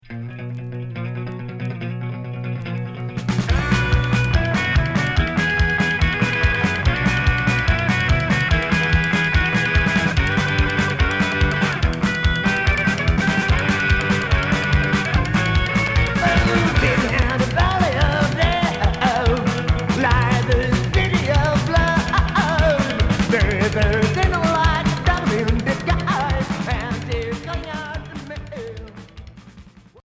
Psychobilly